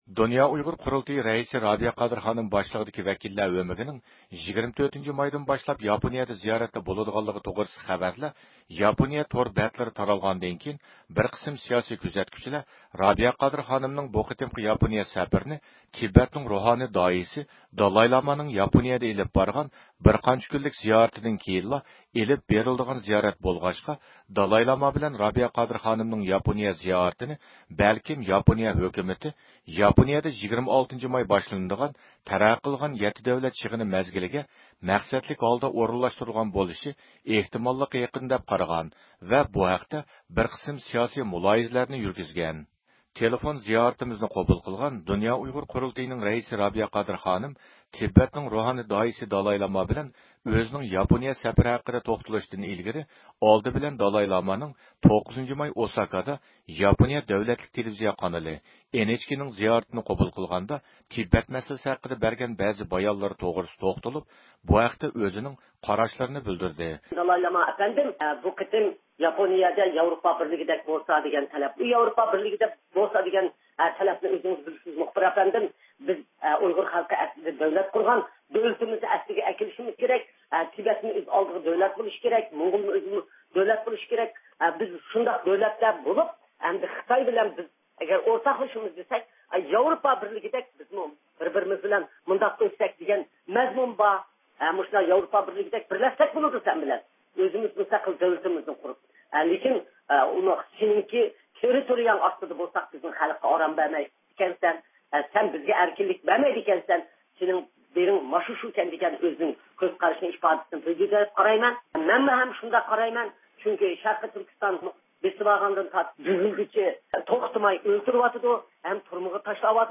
دۇنيا ئۇيغۇر قۇرۇلتىيىنىڭ رەئىسى رابىيە قادىر خانىمنىڭ 24-مايدىن باشلاپ ياپونىيەدە ئېلىپ بارىدىغان زىيارىتى ھەققىدە ياپونىيە تور بەتلىرىدە تۈرلۈك ئىنكاس ۋە مۇلاھىزىلەر يۈرگۈزۈلگەن بولۇپ، بىز بۇ ھەقتە بىر قىسىم جاۋابقا ئىگە بولۇش ئۈچۈن رابىيە قادىر خانىم بىلەن تېلېفون سۆھبىتى ئېلىپ باردۇق.